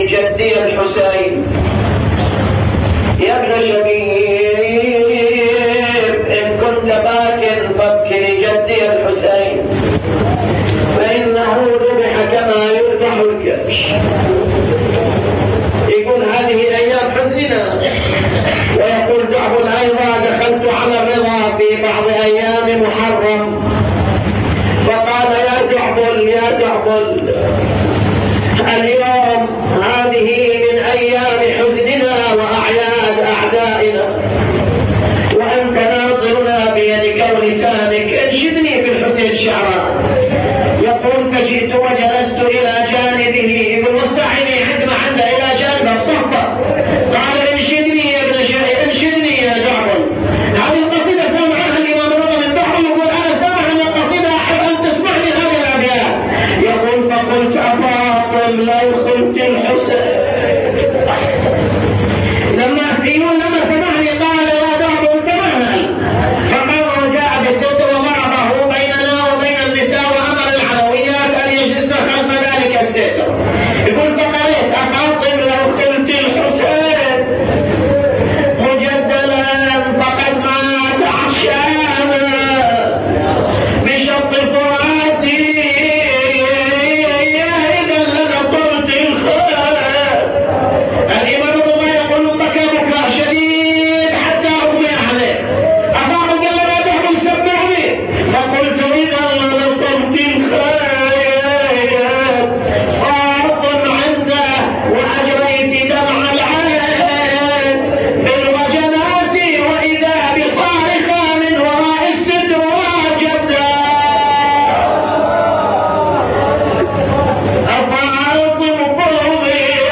أبيات حسينية